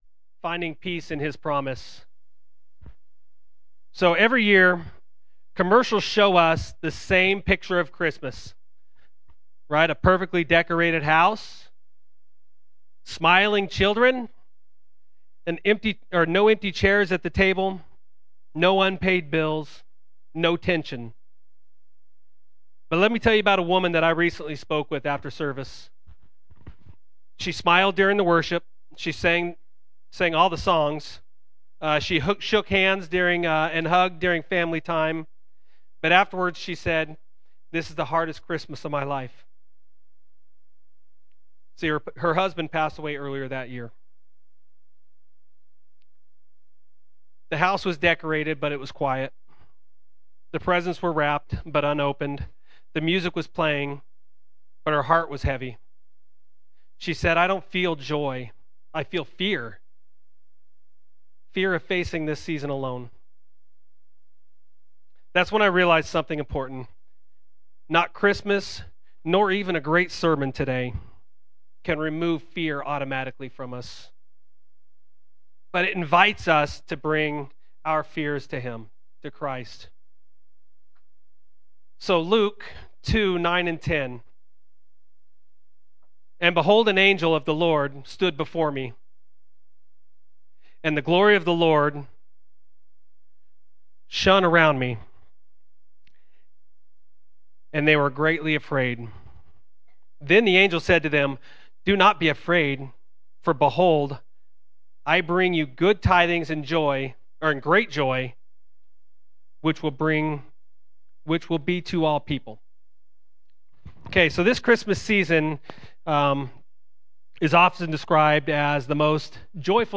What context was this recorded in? Sunday Evening Service December 14, 2025 – Do Not Be Afraid: Finding Peace In The Promise